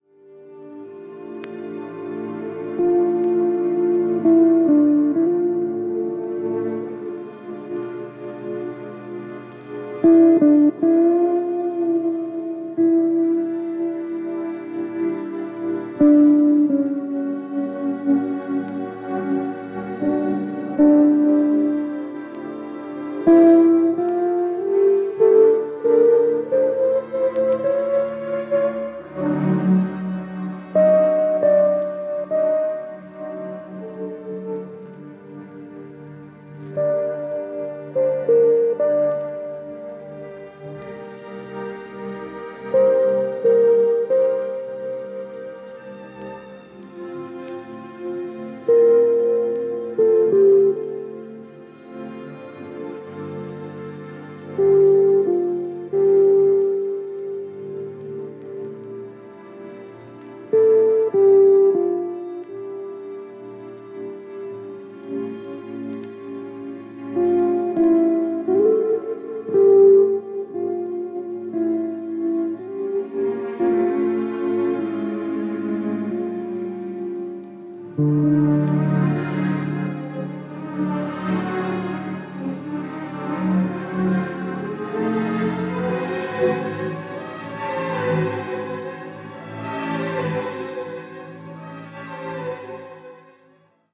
クラシカルなサウンドが今の季節丁度良いですね！！！